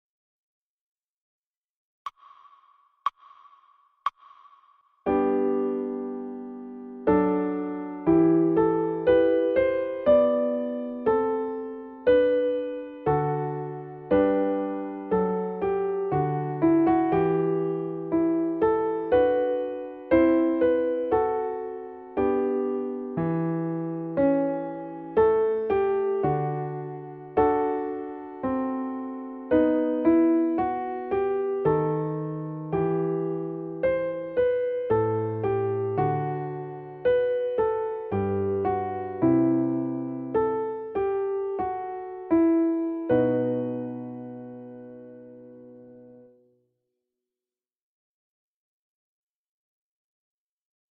Handel Adagio Backing Track